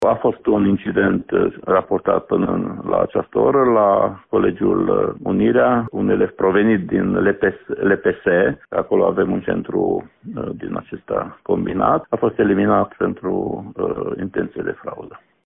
Un elev a fost eliminat din examen pentru că a încercat să copieze, a arătat inspectorul școlar general Ștefan Someșan: